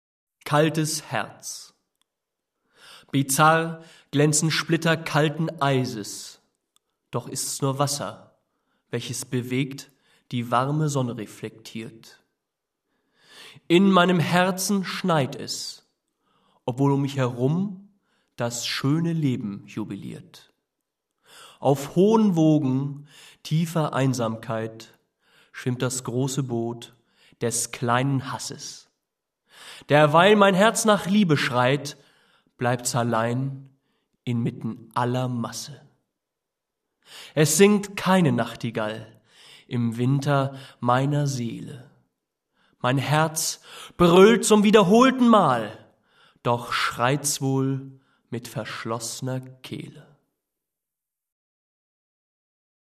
typische poetische Varieté-Stimmung